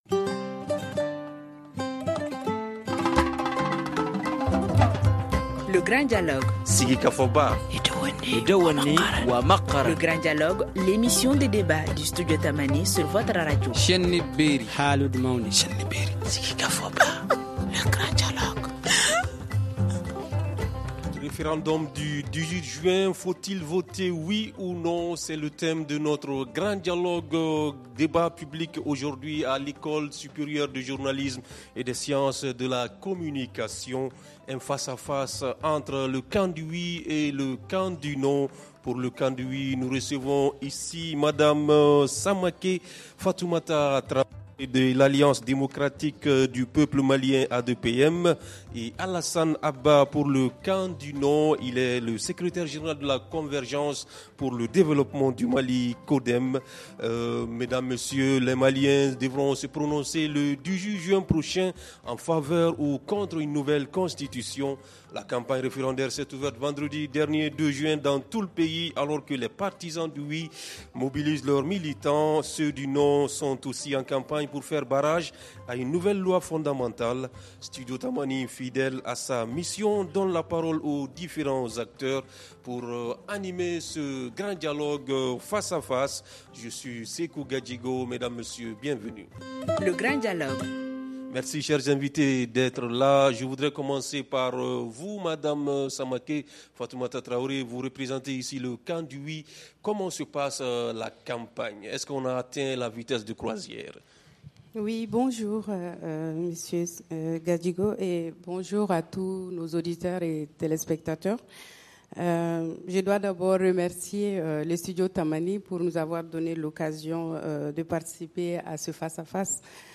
Studio tamani fidèle à sa mission donne la parole aux différents acteurs ici à l’Ecole supérieure de journalisme et des sciences de la communications ESJSC.
Nous recevons aujourd’hui deux invités :